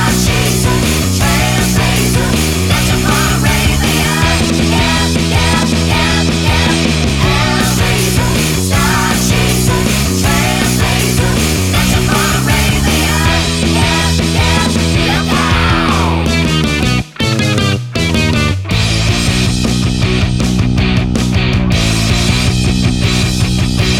no Backing Vocals Glam Rock 3:19 Buy £1.50